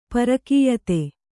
♪ parakīyate